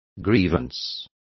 Complete with pronunciation of the translation of grievances.